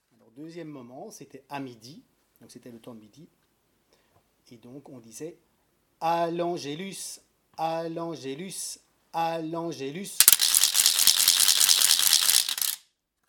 Genre : chant
Type : chanson calendaire
Instrument(s) : crécelles
Lieu d'enregistrement : Institut Supérieur Royal de Musique et de Pédagogie (Namur)